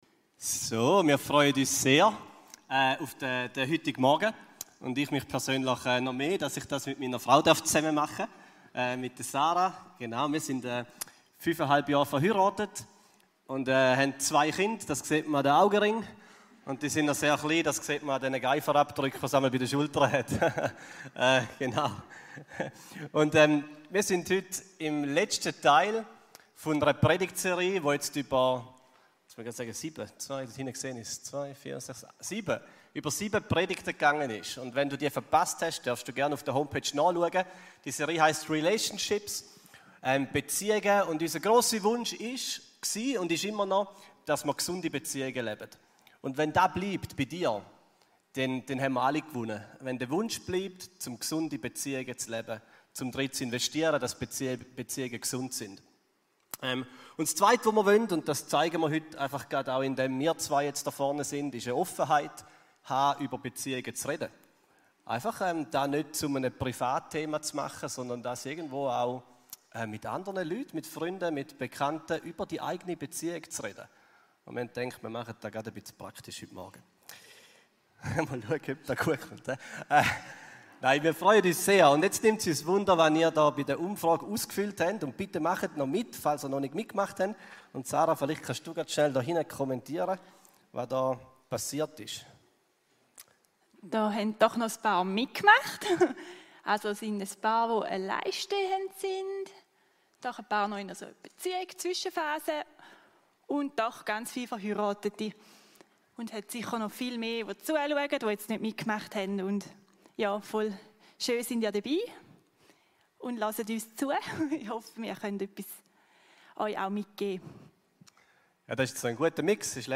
REALationships – Predigtserie